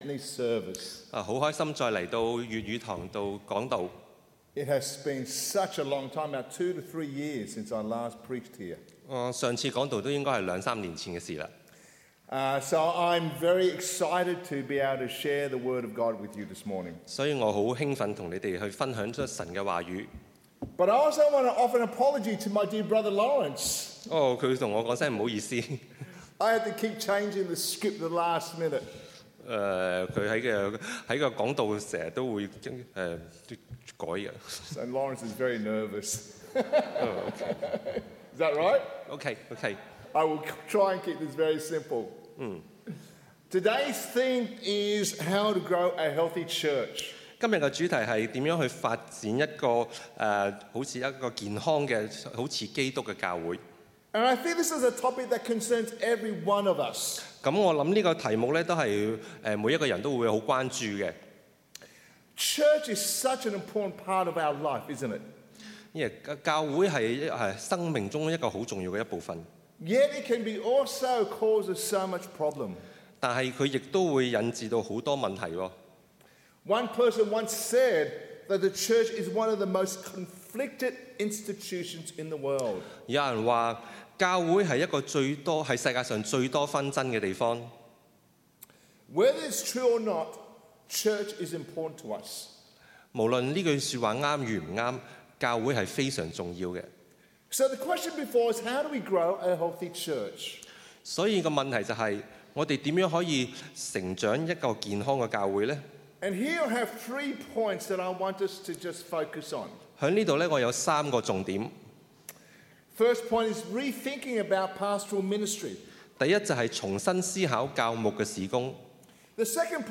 Cantonese (Weekly Sermons)